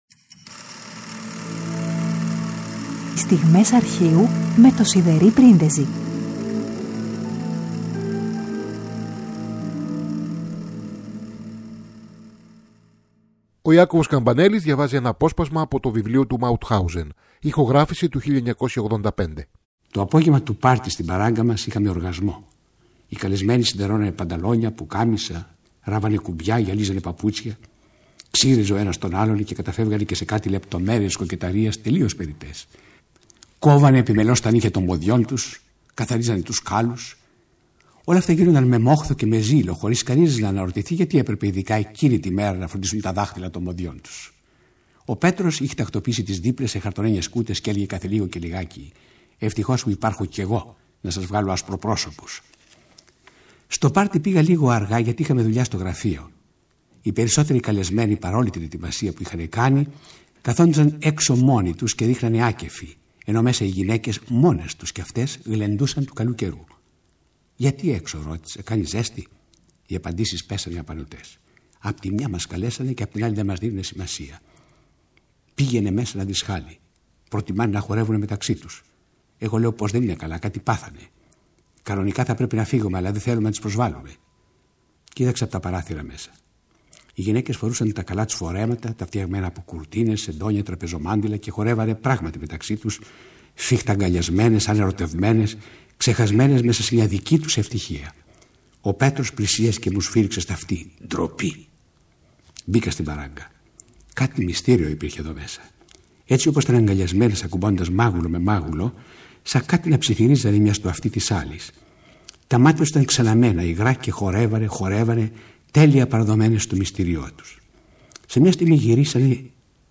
Τρίτη 29 Μαρτίου: Ο Ιάκωβος Καμπανέλλης διαβάζει ένα απόσπασμα από το βιβλίο του «Μαουτχάουζεν» από ηχογράφηση του 1985.